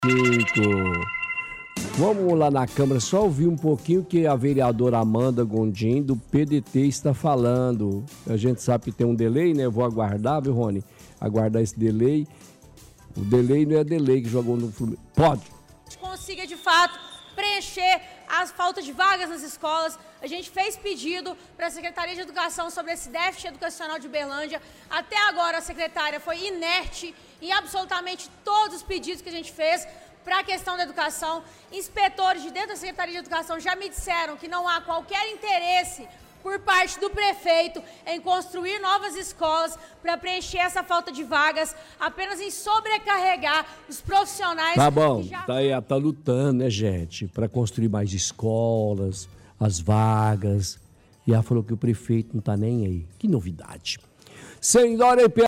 – Ouvinte fala que esteve na câmara municipal semana passada durante a manifestação e que “O trem está pegando”, “Esse ano o blindado não está querendo dá aumento para o povo”.
– Transmite áudio ao vivo da manifestação.